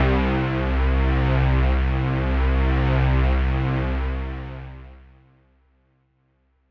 Lush Pad 2 C3.wav